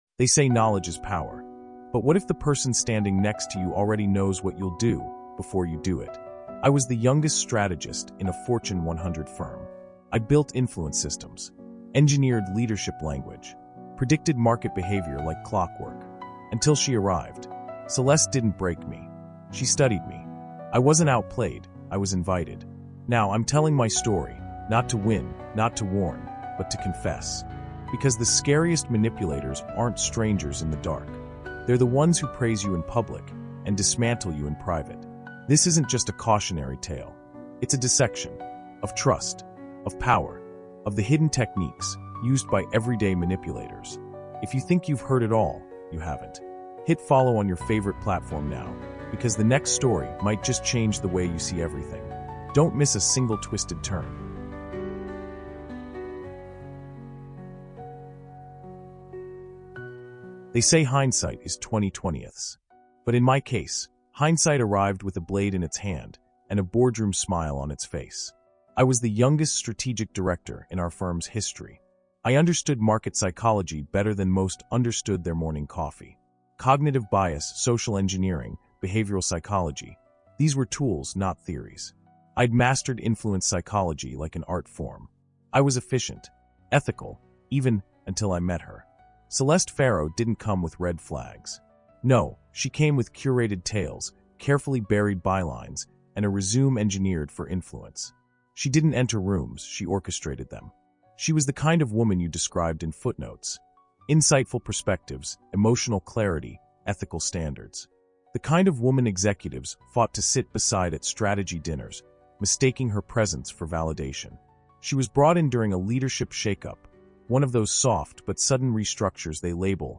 The Hidden Techniques of Everyday Manipulators is a chilling, true-inspired psychological thriller told in immersive first-person narration. This four-chapter audio experience follows a high-level corporate strategist who falls victim to the most dangerous kind of control — the kind that feels like admiration… until it erases everything.